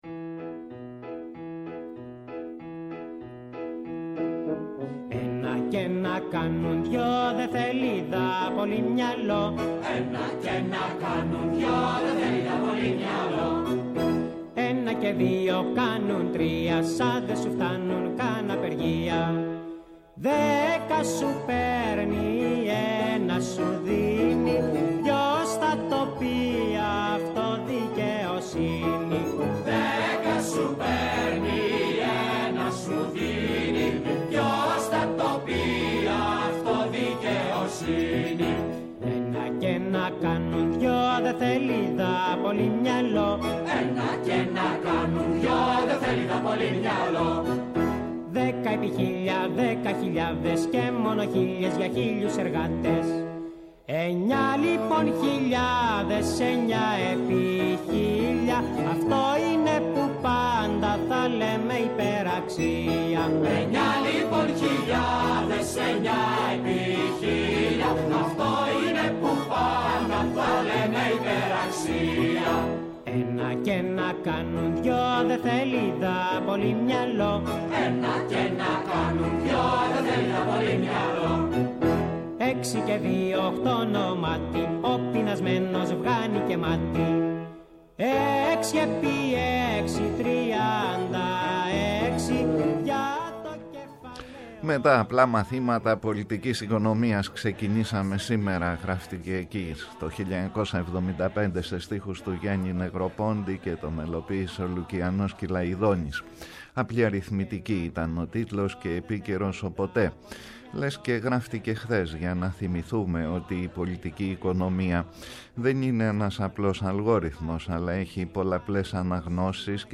-ο Κώστας Ζαχαριάδης, υποψήφιος βουλευτής ΣΥΡΙΖΑ-ΠΣ
-ο Χάρης Καστανίδης, υποψήφιος βουλευτής ΠΑΣΟΚ – ΚΙΝΑΛ
υποψήφιος περιφερειάρχης Αττικής ΚΚΕ Κάθε Παρασκευή 11:00-12:00 , στο Πρώτο Πρόγραμμα της Ελληνικής Ραδιοφωνίας.